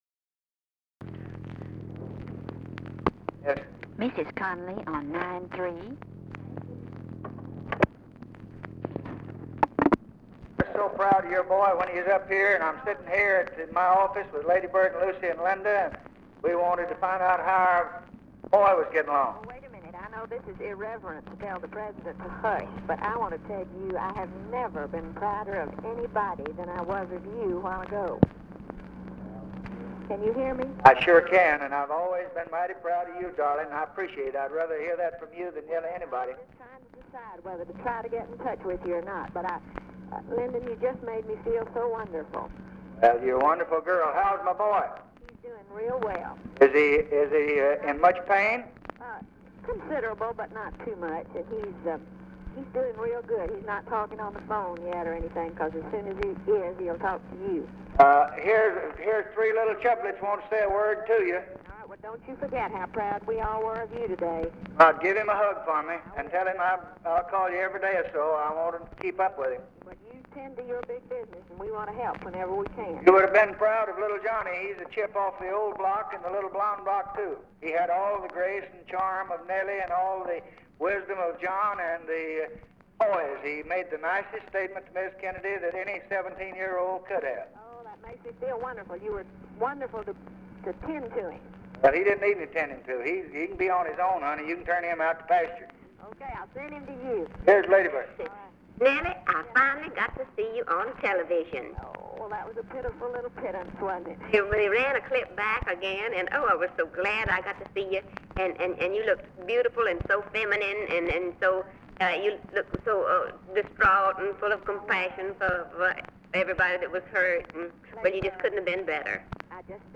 Conversation with NELLIE CONNALLY, November 27, 1963
Secret White House Tapes